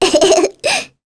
Sonia-vox-Happy1_kr.wav